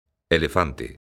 elefante_son.mp3